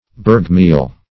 bergmeal - definition of bergmeal - synonyms, pronunciation, spelling from Free Dictionary
Search Result for " bergmeal" : The Collaborative International Dictionary of English v.0.48: Bergmeal \Berg"meal\, n. [G. berg mountain + mehl meal.]